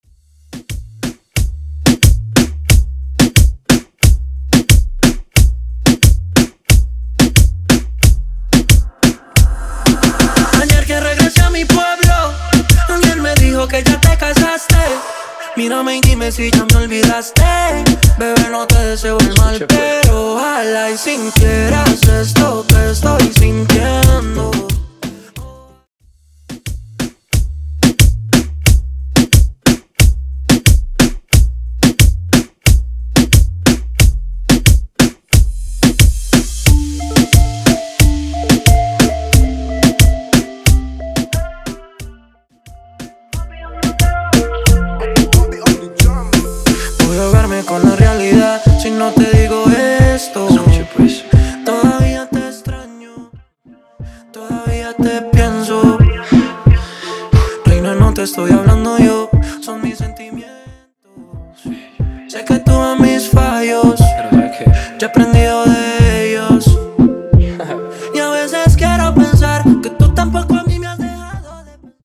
Intro Acapella Dirty, Intro Dirty (2 Versiones)